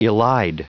Prononciation du mot elide en anglais (fichier audio)
Prononciation du mot : elide